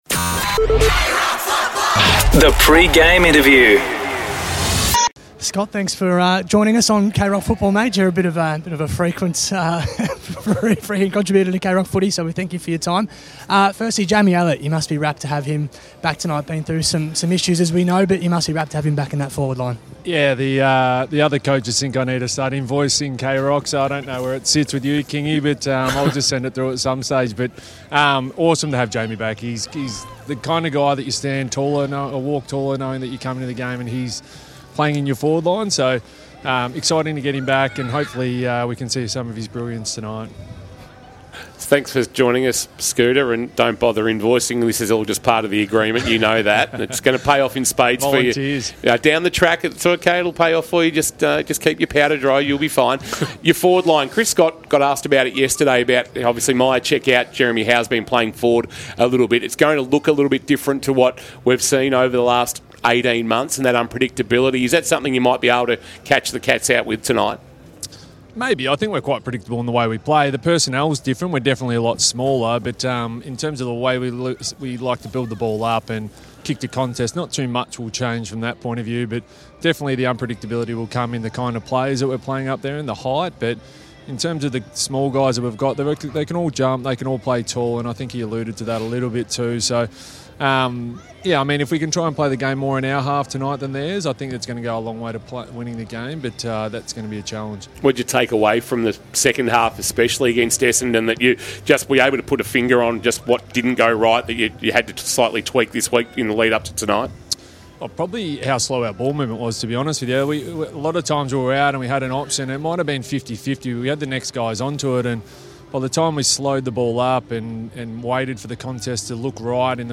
2024 - AFL - Round 18 - Collingwood vs. Geelong: Pre-match interview - Scott Selwood (Collingwood assistant coach)